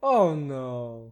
Add voiced sfx
ohno2.ogg